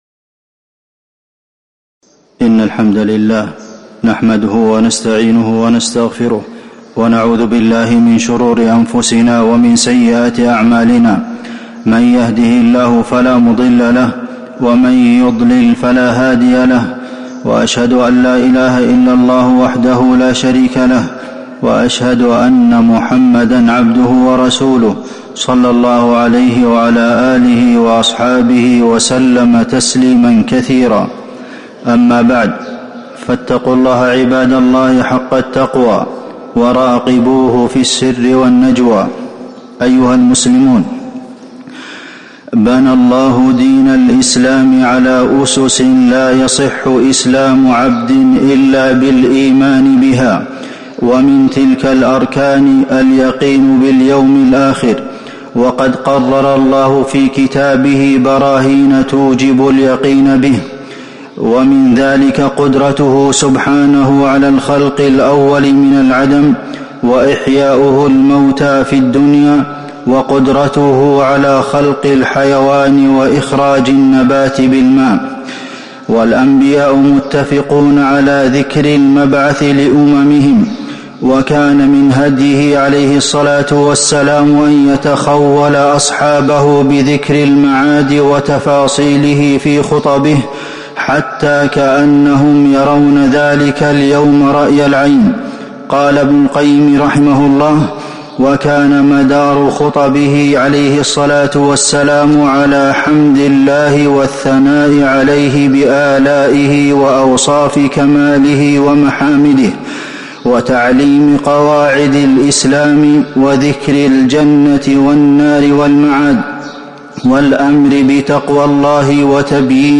تاريخ النشر ٢٦ رجب ١٤٤٤ هـ المكان: المسجد النبوي الشيخ: فضيلة الشيخ د. عبدالمحسن بن محمد القاسم فضيلة الشيخ د. عبدالمحسن بن محمد القاسم اليقين باليوم الآخر